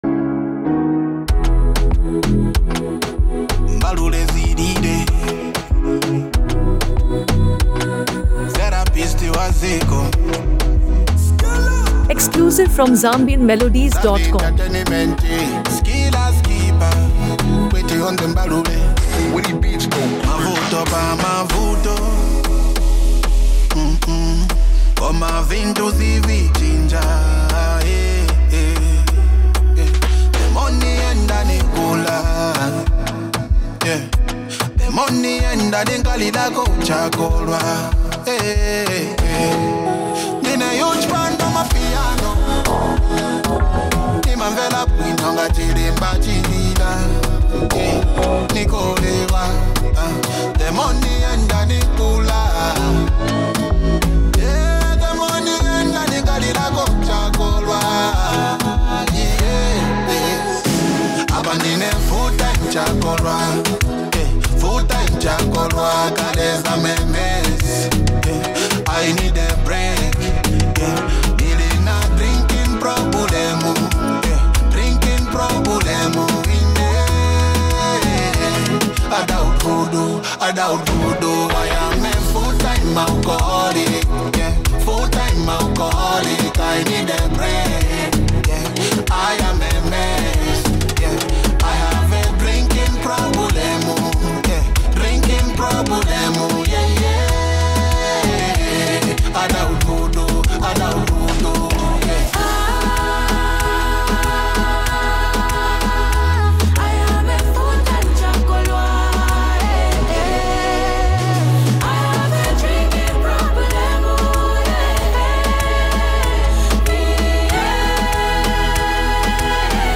Genre: Afro Pop/Amapiano